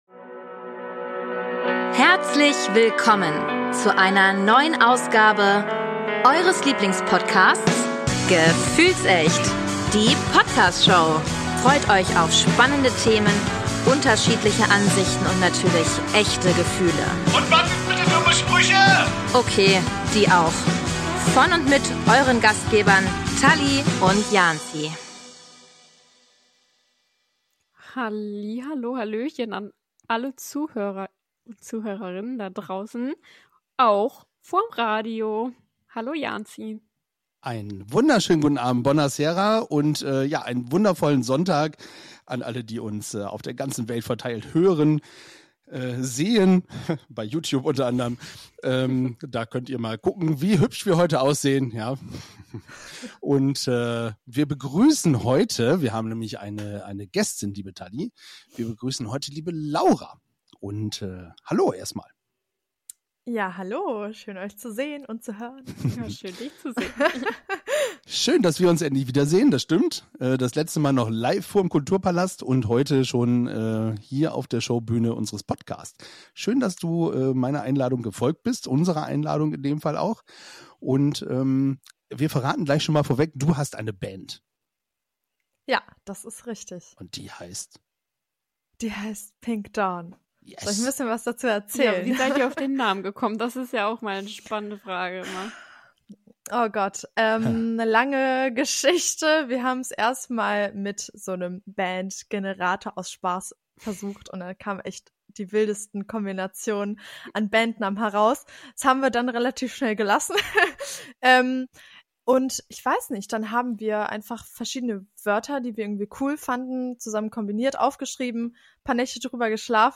Der Hauptteil der aktuellen Folge befasst sich mit den Top 5 Sommerhits der 3 Protagonisten. Hier wird gesungen was das Zeug hält.